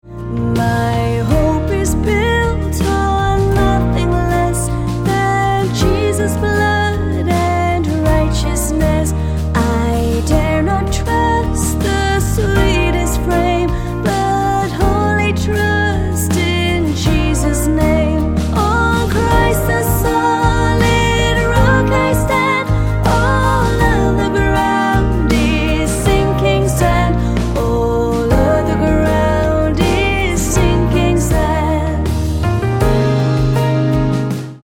Eb